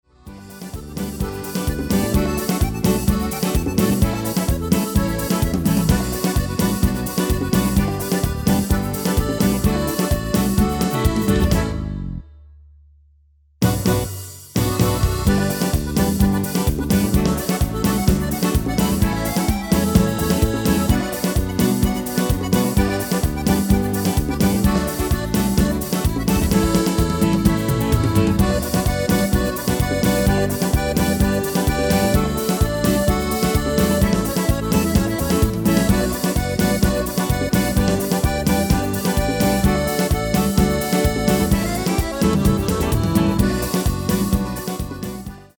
Demo/Koop midifile
Genre: Reggae / Latin / Salsa
Toonsoort: B
- Vocal harmony tracks